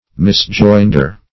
Misjoinder \Mis*join"der\, n. (Law)